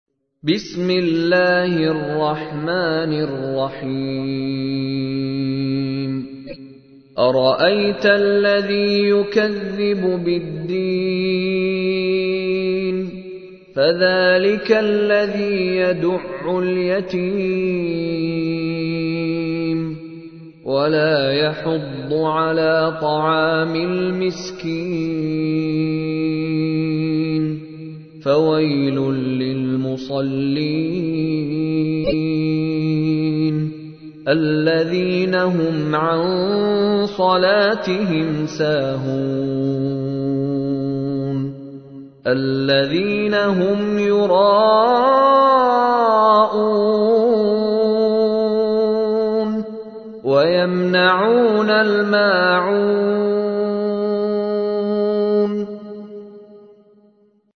تحميل : 107. سورة الماعون / القارئ مشاري راشد العفاسي / القرآن الكريم / موقع يا حسين